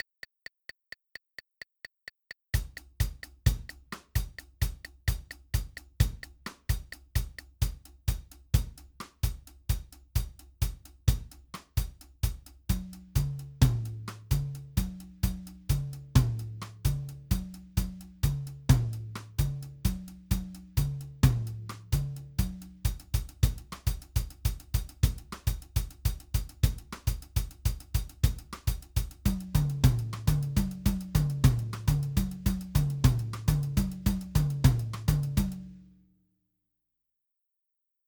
в принципе ритм получается как то так
kopanitsa 11-16 rhythm.mp3